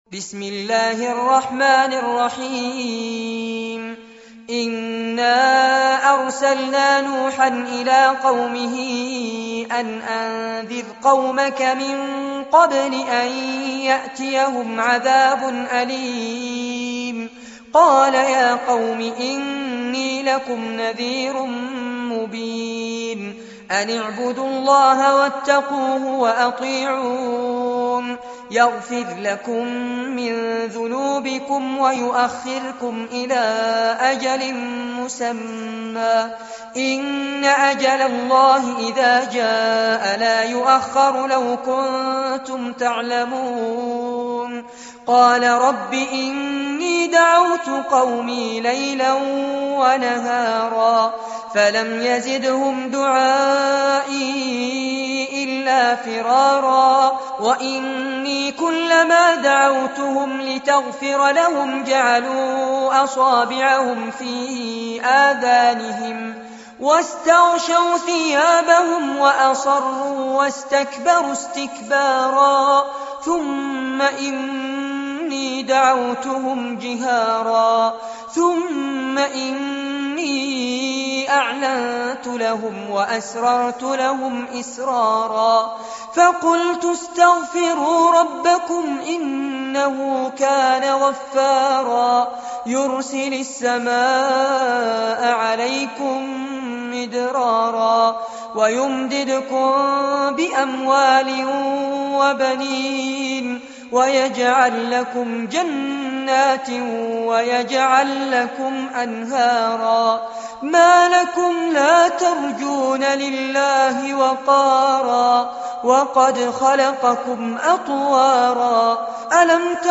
سورة نوح- المصحف المرتل كاملاً
جودة عالية